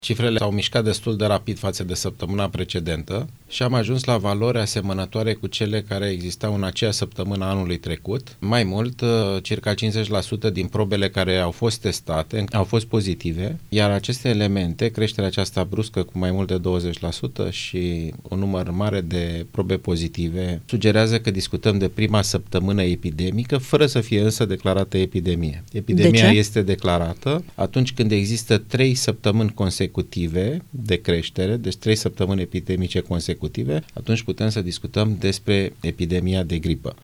Numărul cazurilor de gripă a crescut simţitor în România, cu 20% faţă de săptămâna trecută, a declarat Alexandru Rafila, preşedintele Societăţii Române de Microbiologie, în emisiunea Piaţa Victoriei de la Europa FM.